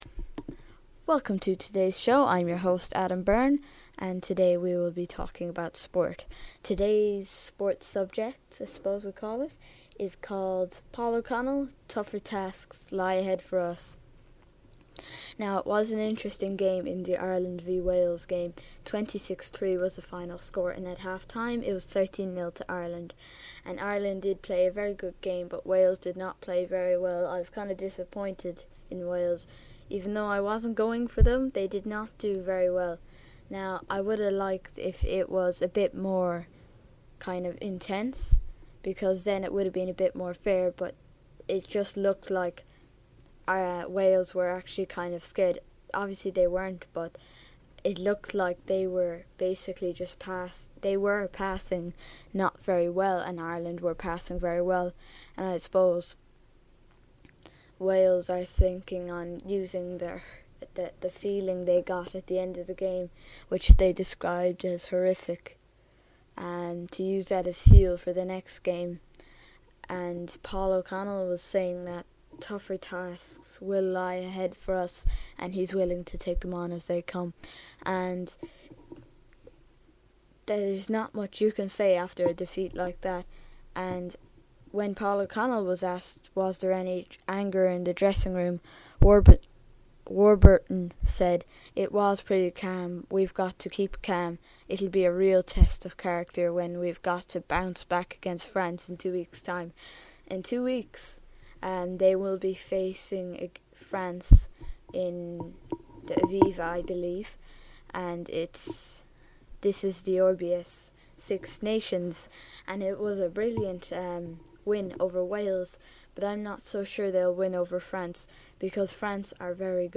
A report on The Ireland v Wales Game!